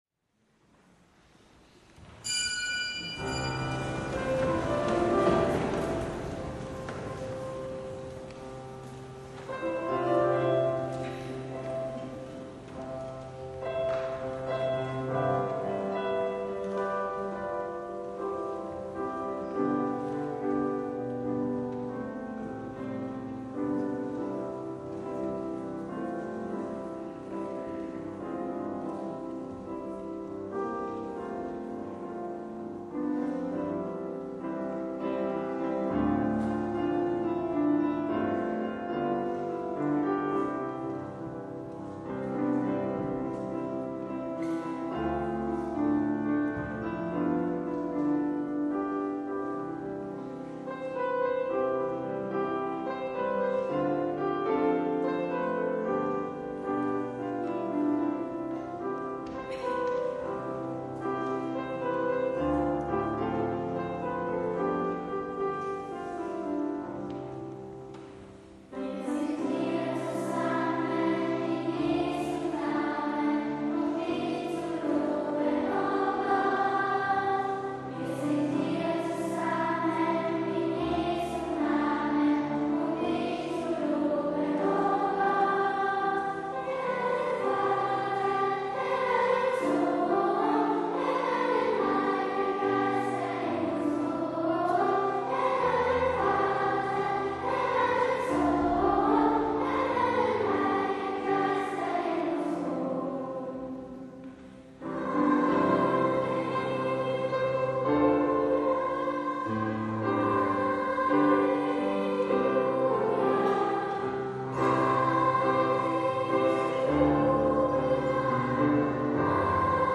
Eucharistiefeier mit Bischof Dr. Alois Schwarz
Eröffnung: Wir sind hier zusammen, Lp 484 4 MB Kinderchor, NGL, Alle